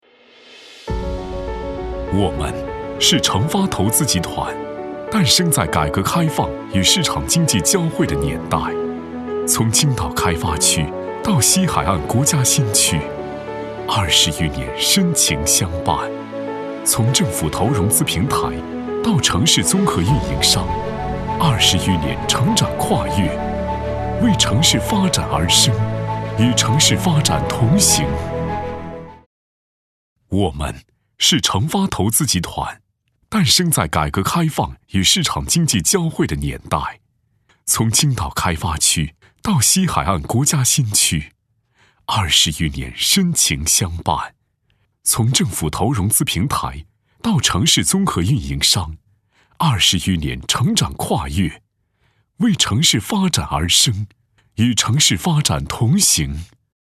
红樱桃配音，真咖配音官网—专业真人配音服务商！